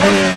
rr3-assets/files/.depot/audio/sfx/gearshifts/f1/honda_downshift_1.wav
honda_downshift_1.wav